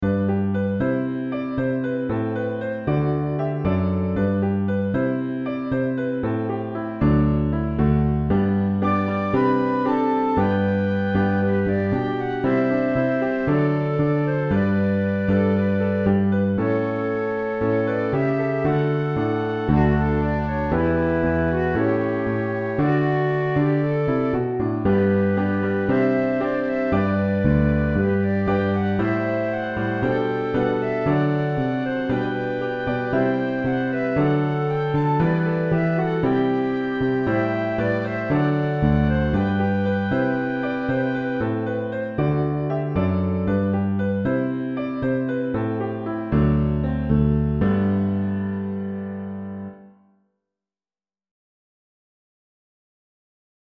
third pair) all feature a flute sound, they can be played by any instrument fitting for worship.